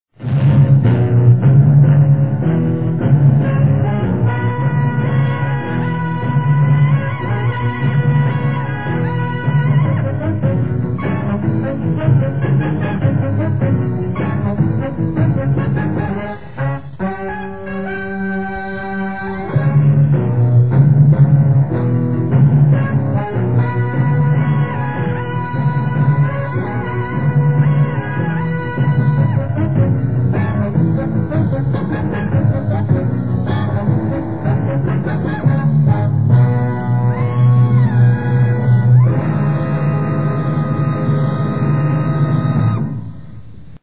Closing theme to season one.